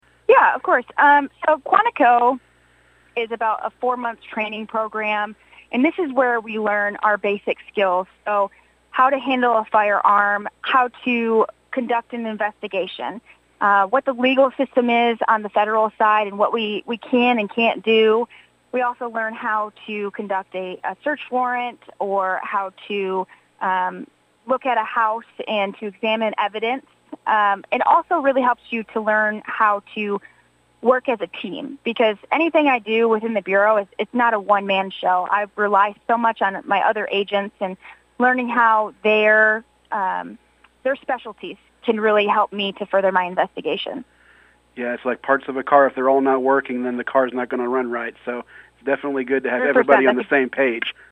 the Cromwell News Team held a phone interview